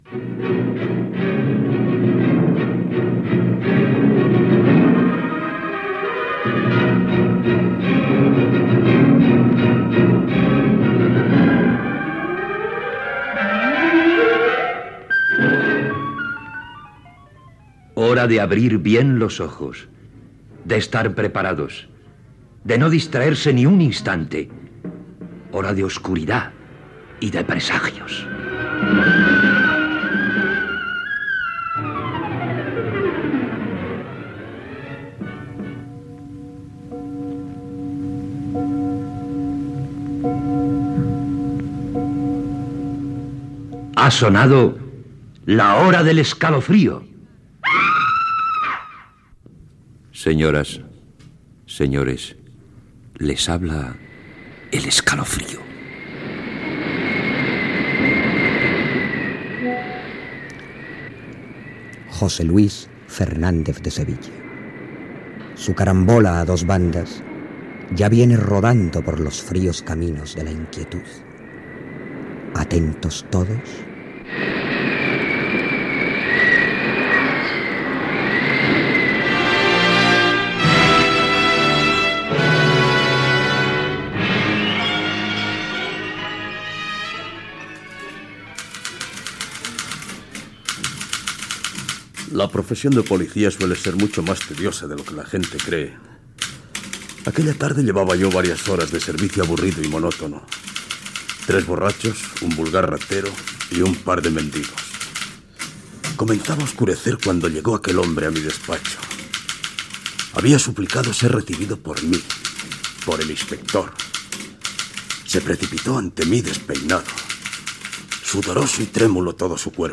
Fragment d'una ficció radiofònica de misteri.